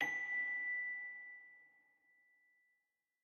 celesta1_11.ogg